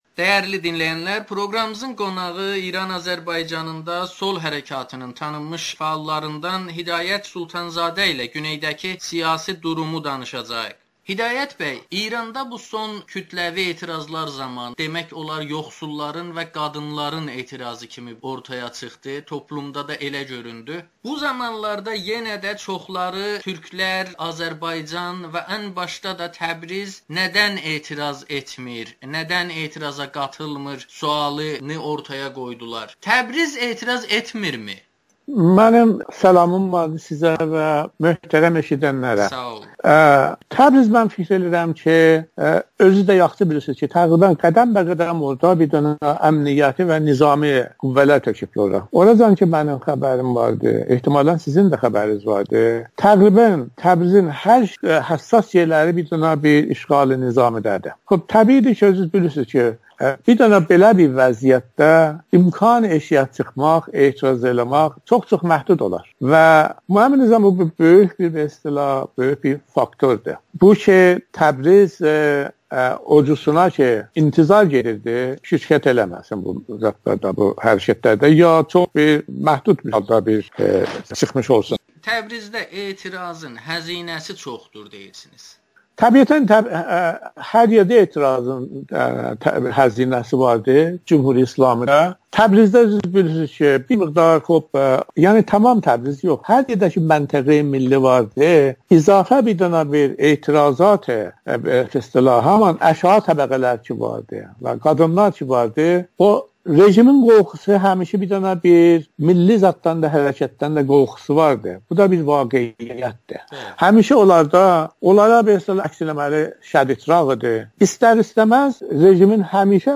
Türk dilində təhsil məsələsini siyasi qüdrət məsələsindən ayırmaq olmaz [Audio-Müsahibə]